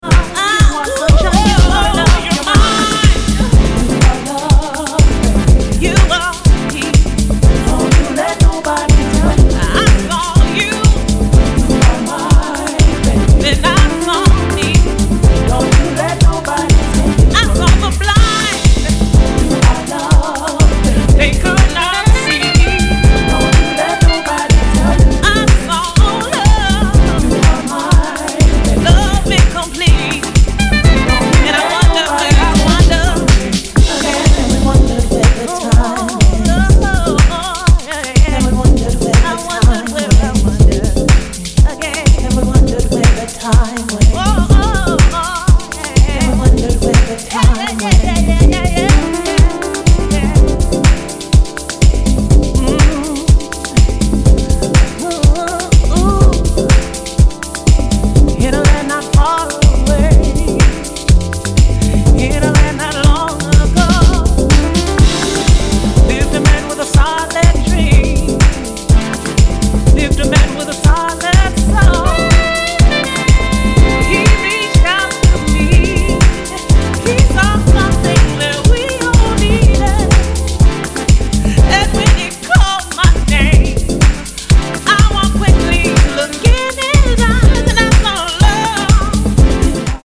ダンス・フロア向けにビートと空間性が強化された、さすがの仕事振りを披露しています。